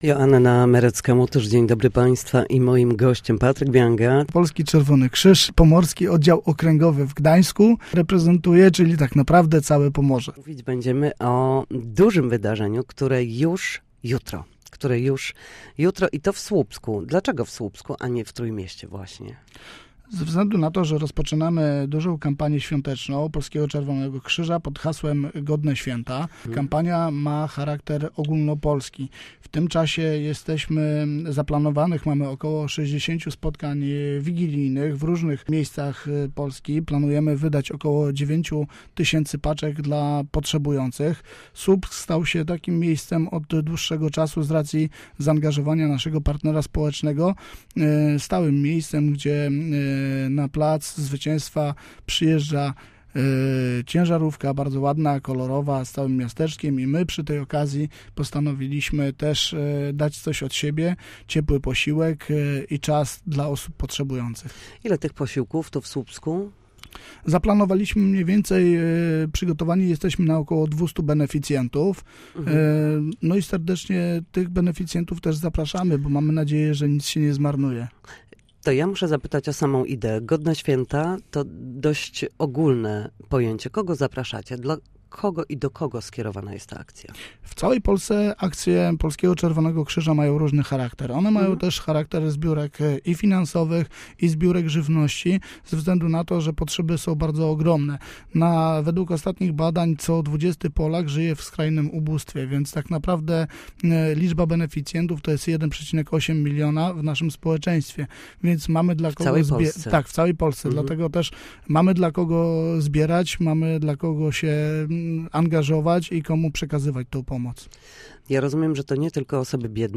Tego typu wydarzenia organizowane przez Polski Czerwony Krzyż odbędą się w 60 miejscach w Polsce, wsparcie otrzyma około 9 tysięcy osób – dodaje. Posłuchaj rozmowy